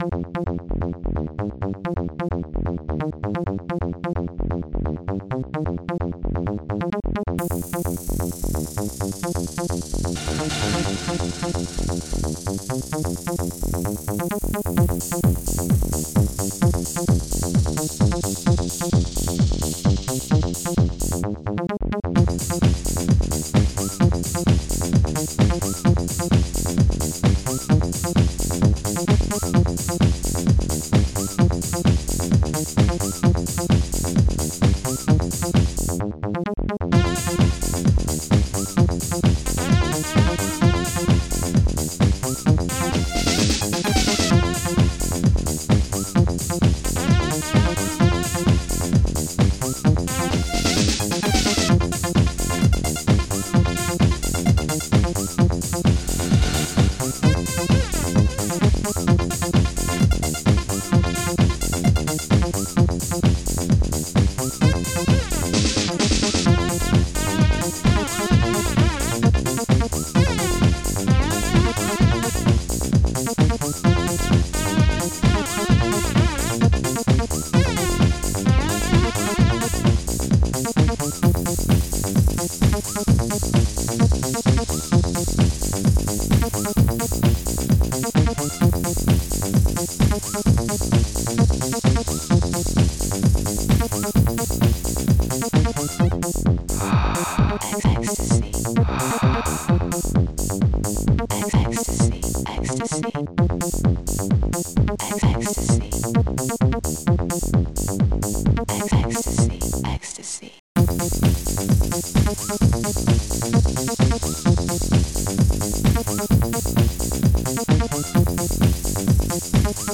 dance / acid_tekno